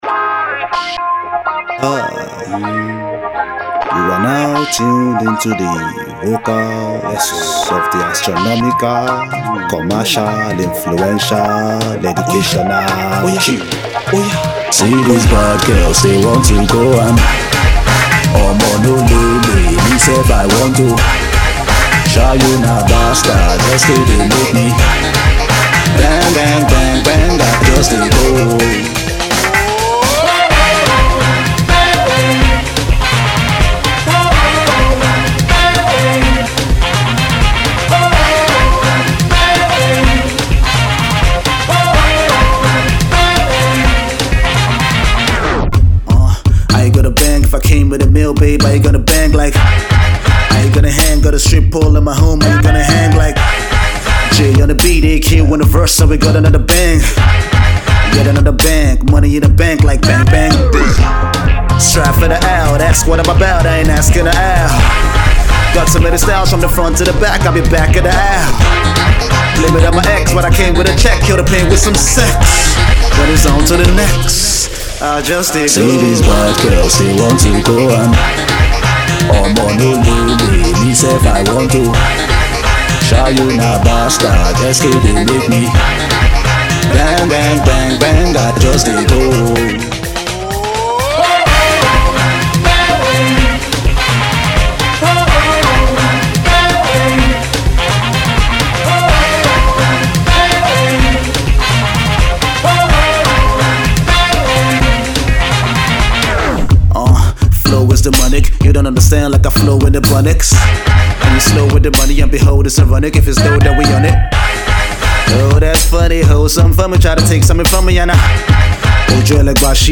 The afrobeat meet rap musical backdrop is a masterpiece.